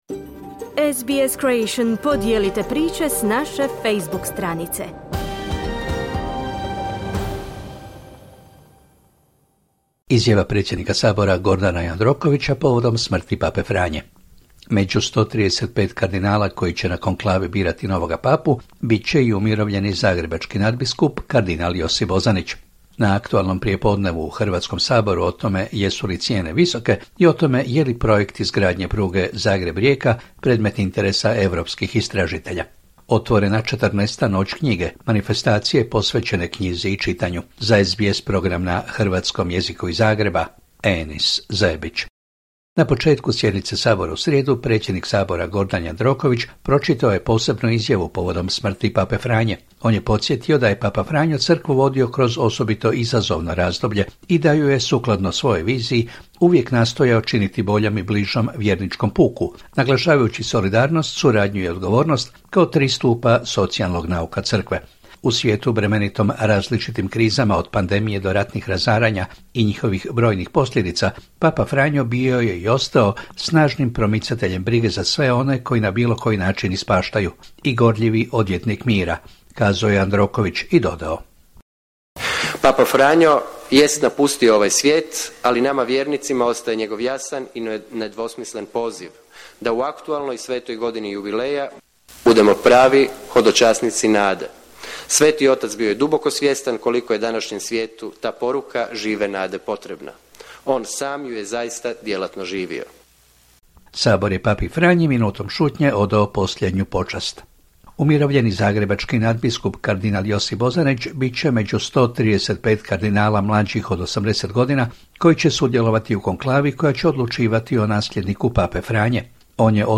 Izjava predsjednika Sabora Gordana Jandrokovića povodom smrti pape Franje. Među 135 kardinala koji će na konklavi birati novog papu biti će i umirovljeni zagrebački nadbiskup, kardinal Josip Bozanić.